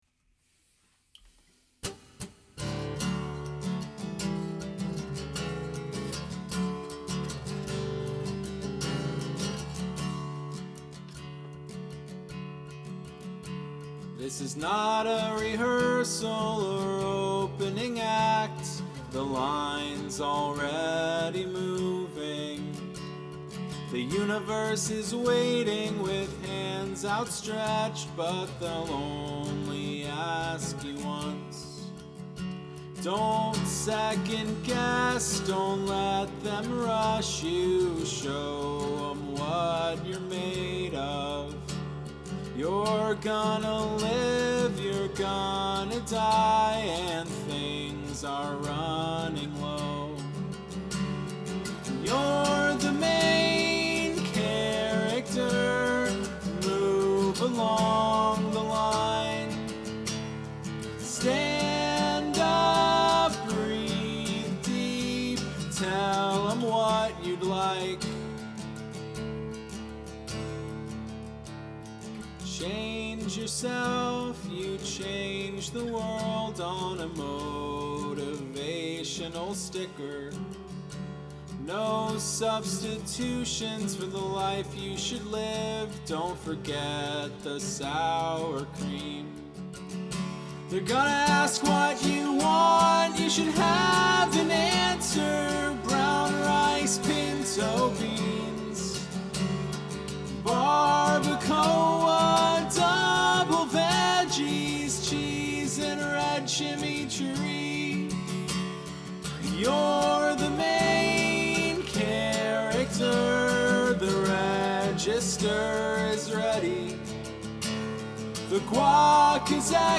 That soaring vocal in the bridge?